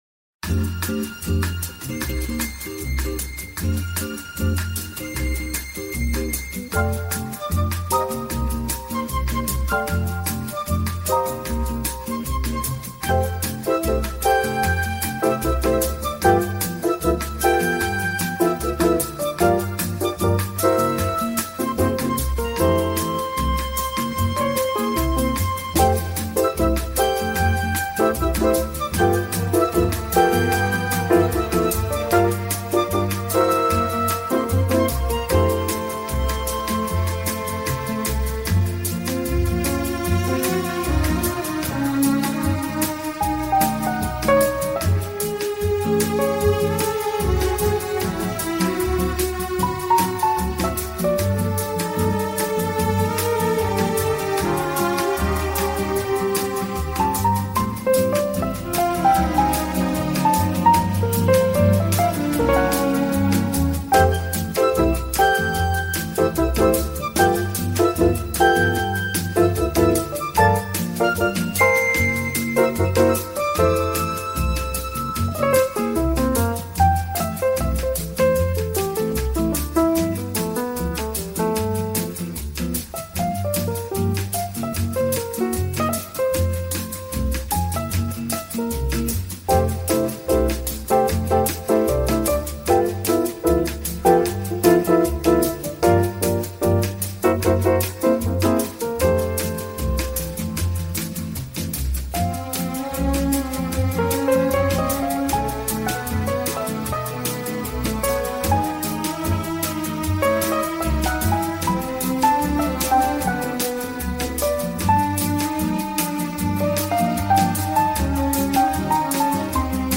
KGeumHGhrrJ_vintage-brazil-bossa-nova-fnxCNdKz.mp3